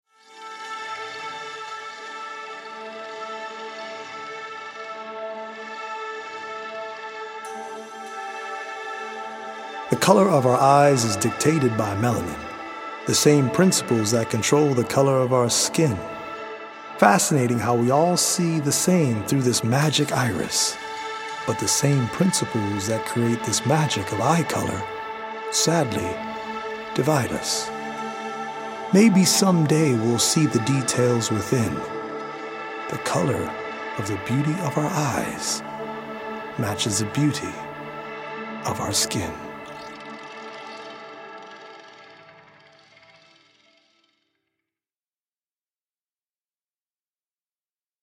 poetic recordings
healing Solfeggio frequency music